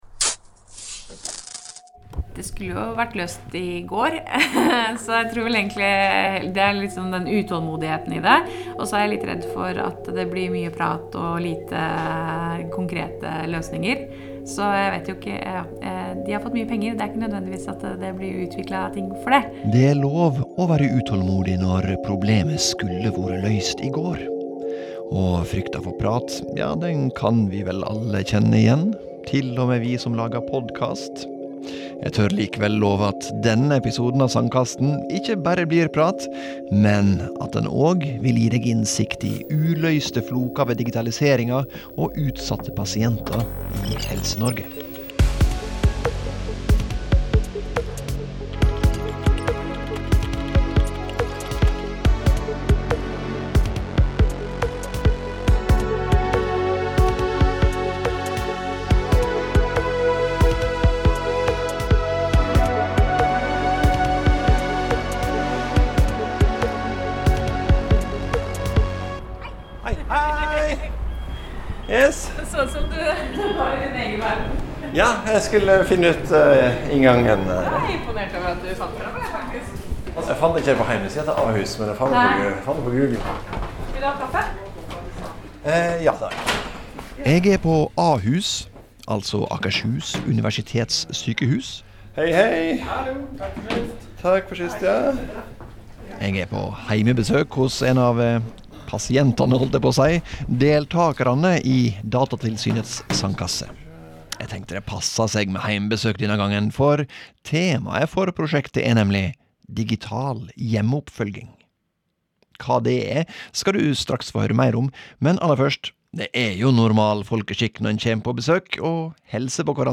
Gjennom samtaler med sykepleiere, jurister og teknologer får vi høre at Ahus har begynt å bruke digitale verktøy for å følge opp pasienter når de ikke er på sykehuset.